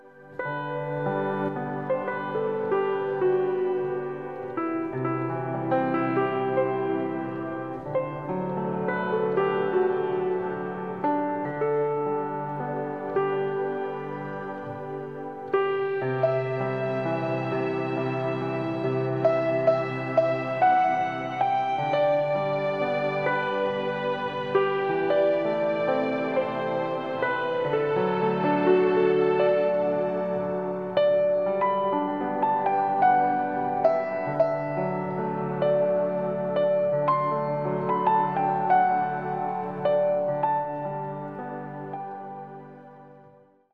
Soothing, uplifting, harmonizing and healing.
INSPIRING AND UPLIFTING